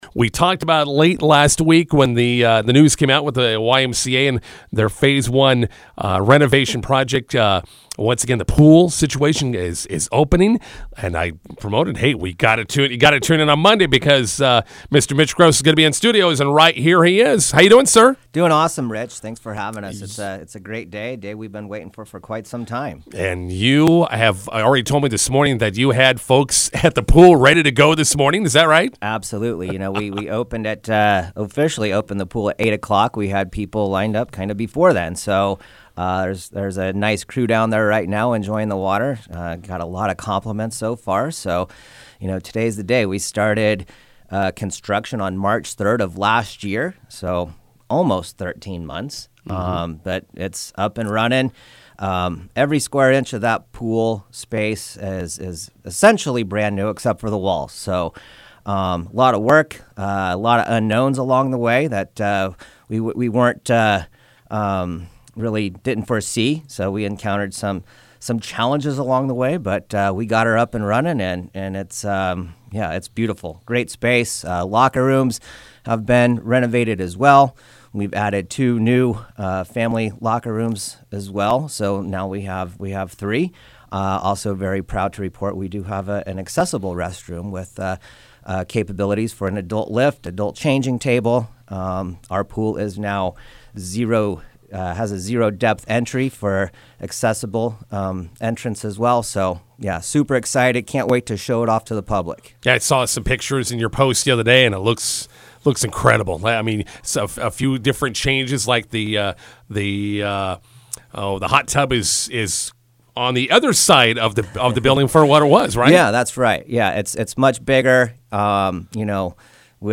INTERVIEW: McCook YMCA Aquatic Center opens today.